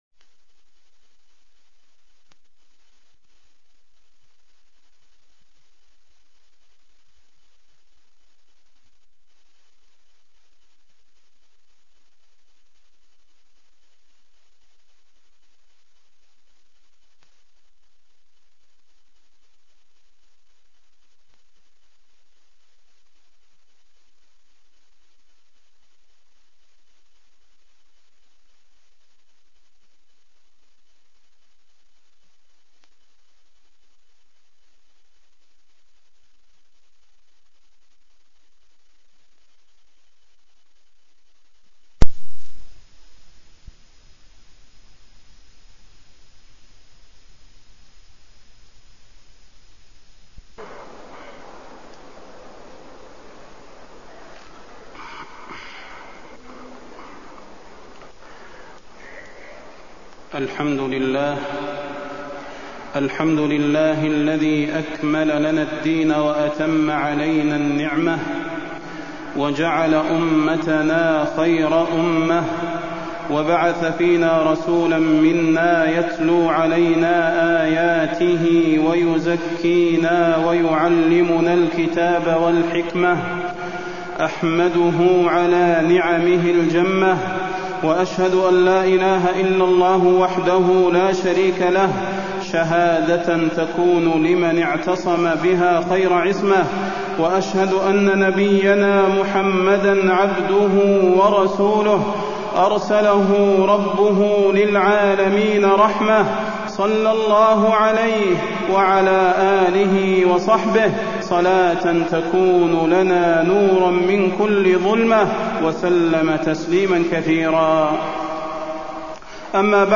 تاريخ النشر ٢٠ جمادى الآخرة ١٤٢٥ هـ المكان: المسجد النبوي الشيخ: فضيلة الشيخ د. صلاح بن محمد البدير فضيلة الشيخ د. صلاح بن محمد البدير اتباع السنة النبوية